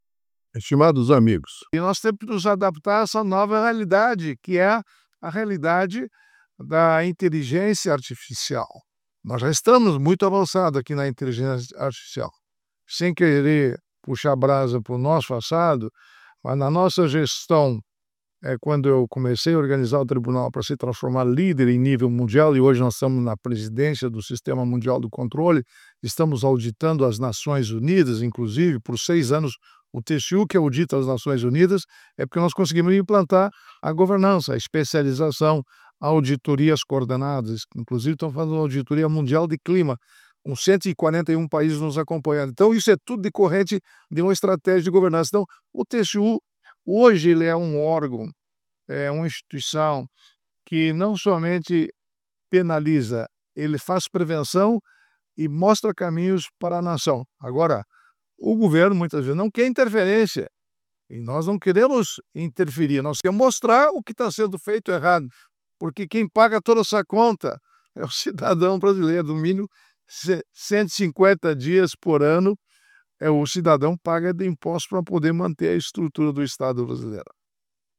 Comentário de Augusto Nardes, ministro do órgão fiscalizador.